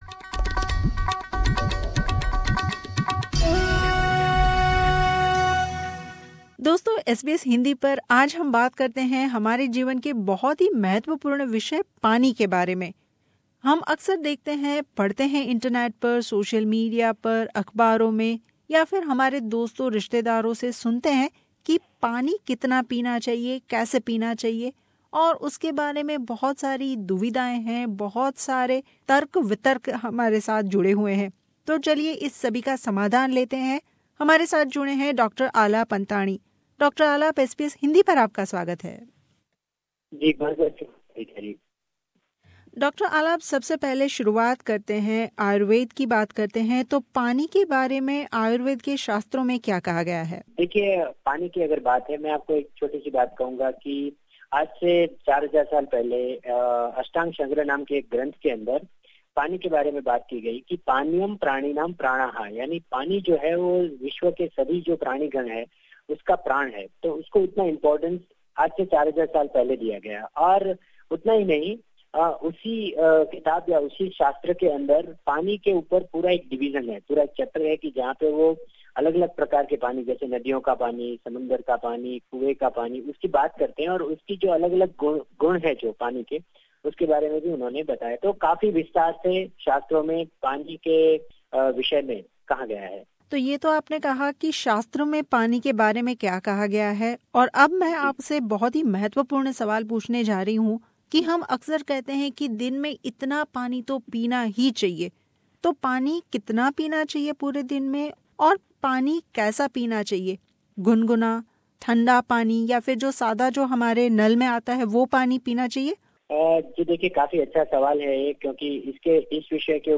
मुलाकात